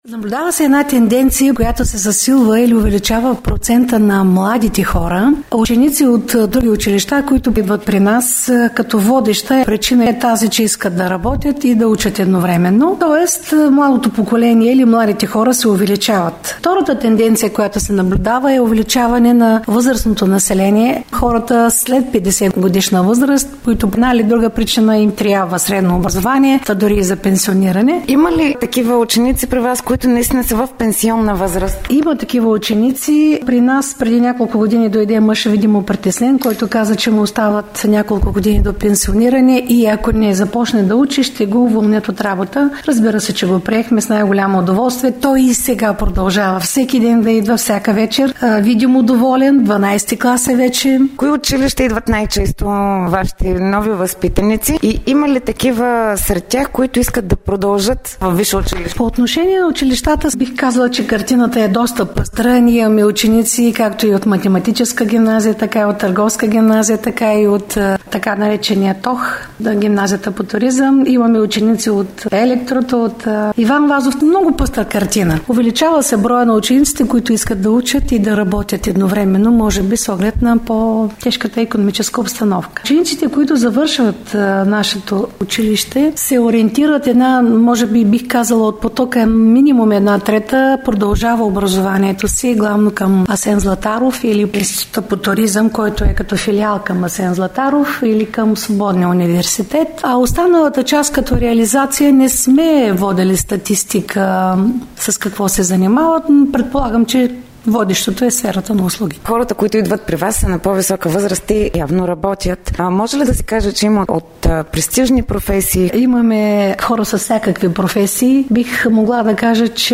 Това каза в ефира на Дарик